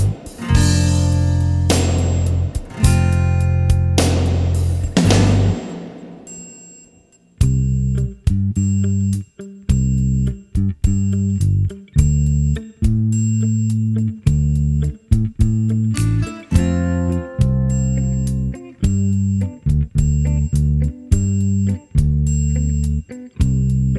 no piano Pop (1980s) 5:24 Buy £1.50